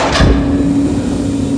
doormove1.wav